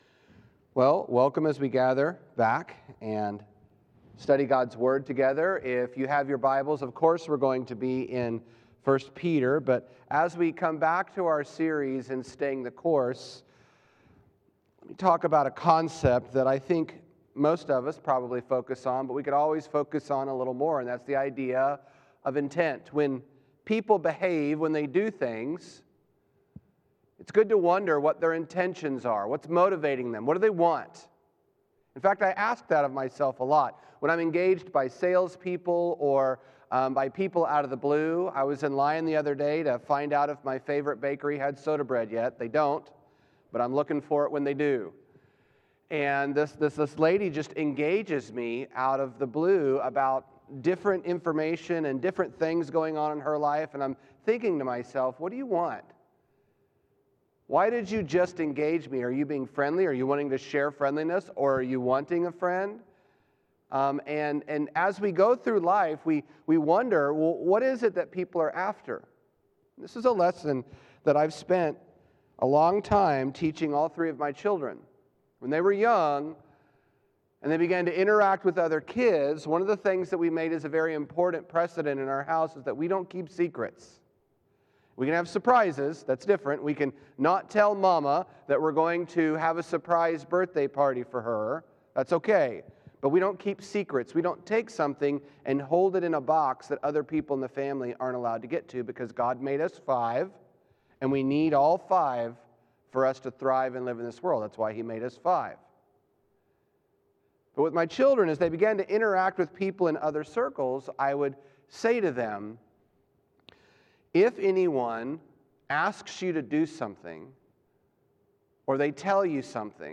(English) Sermon Series: 2 Peter – The Emptiness of Deceit